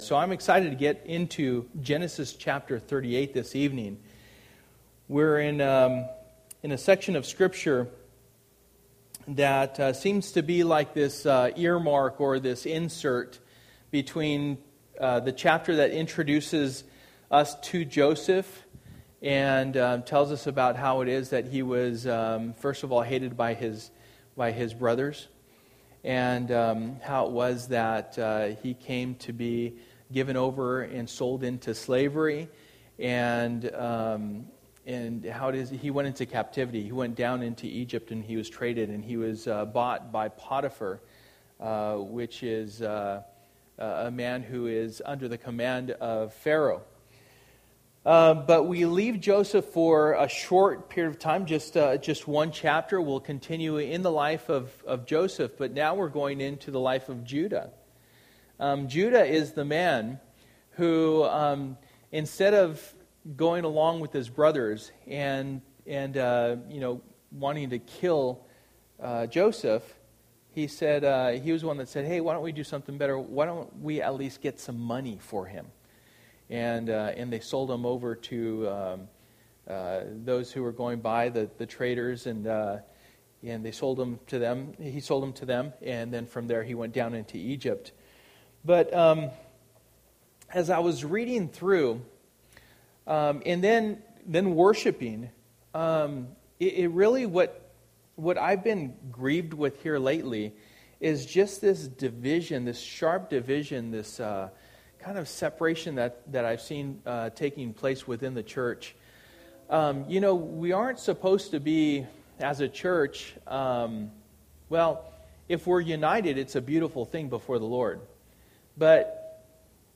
Through the Bible Passage: Genesis 38:1-30 Service: Wednesday Night %todo_render% « Contend for the Faith